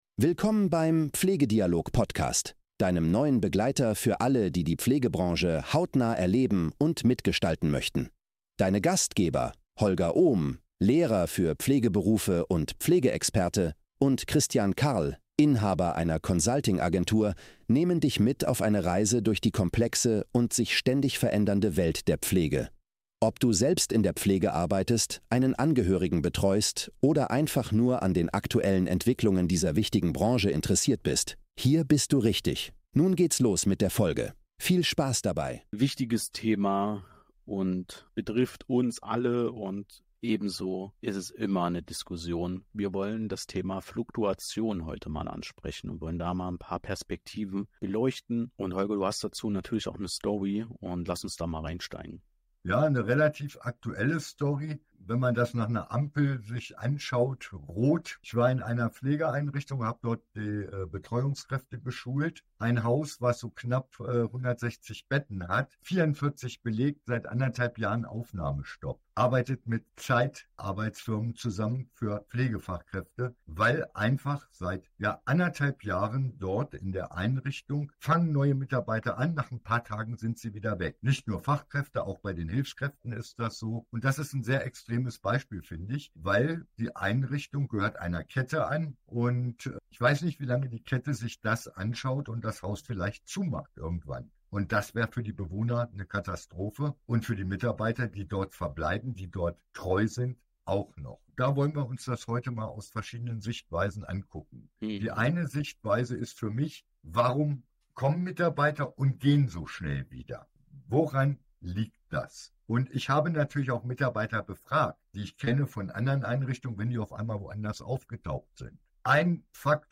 Unsere Experten diskutieren, wie unzureichende Einarbeitung neuer Mitarbeiter und eine mangelhafte interne Kommunikation die Integration erschweren. Besonders betont wird die Notwendigkeit einer wertschätzenden Willkommenskultur und der Förderung von Teambuilding sowie flexiblen Arbeitsmodellen.